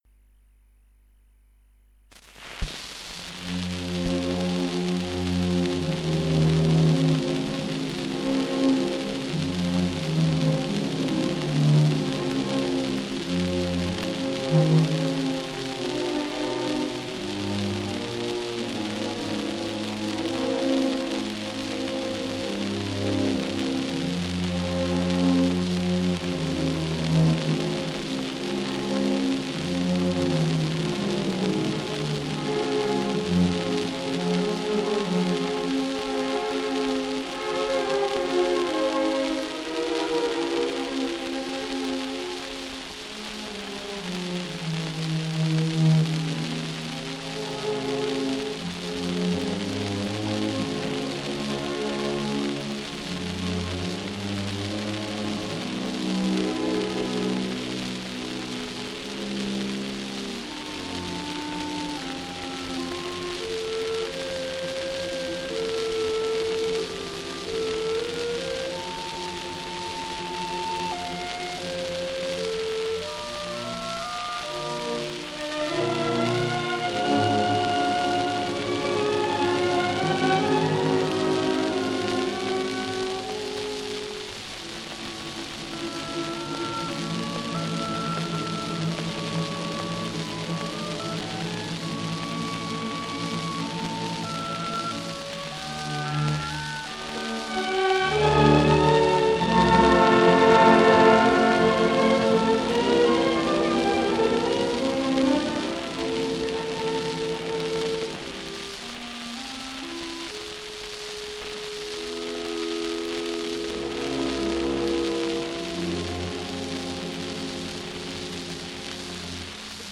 undici dischi a 78 giri del 1943 in cui è registrata la Cavalleria Rusticana
La registrazione è avvenuta a Milano sotto l’egida della celeberrima casa discografica LA VOCE DEL PADRONE.
Orchestra e Coro del Teatro alla Scala.
N 6.- Dite mamma Lucia... Bruna Rasa, sopr – Simionato, contr – Scena 2.a         SCARICA